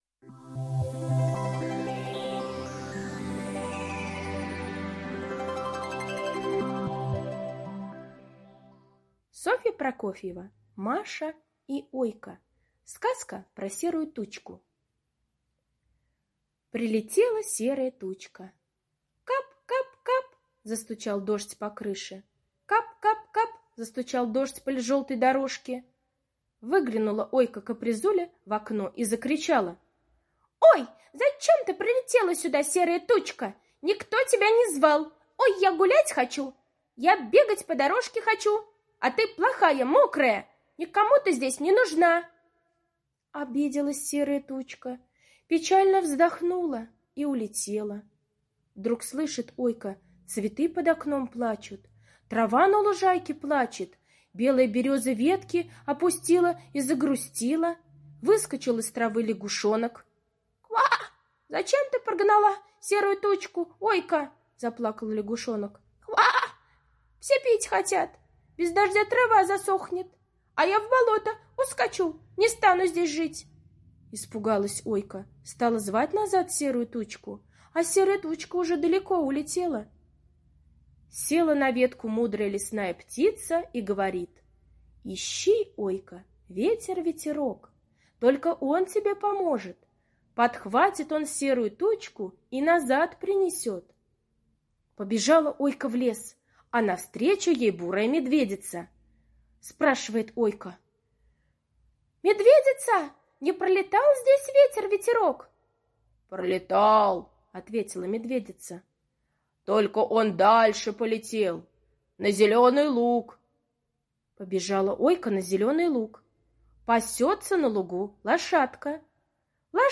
Сказка про Серую Тучку - аудиосказка Прокофьевой С. История о том, как Ойка прогнала Серую Тучку, которая хотела полить землю.